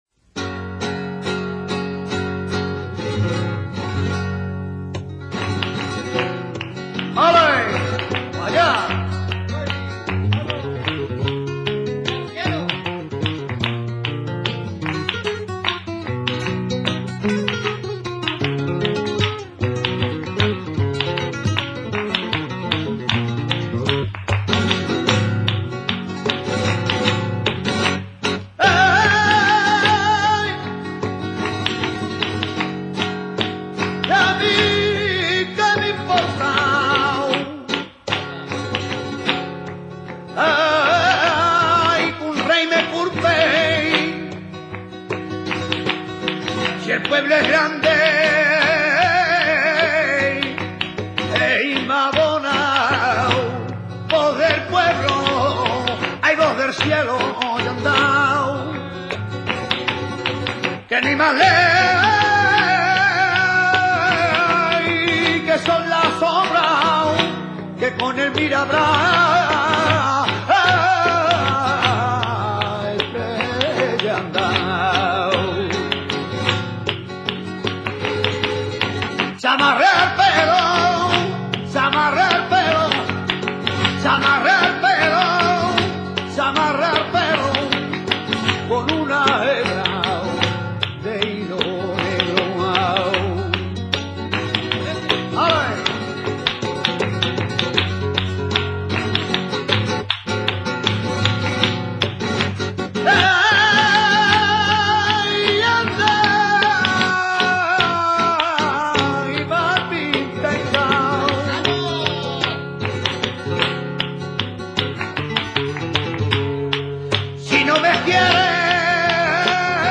Cante con copla de cuatro versos muy irregulares, que el cantaor engarza con otras de las mismas caracter�sticas m�tricas.
Es cante propio para bailar, m�s art�stico que profundo, que exige del cantaor grandes facultades para interpretarlo y que se acompa�a con guitarra, que proporciona una m�sica elegante, �gil y alegre.
mirabras.mp3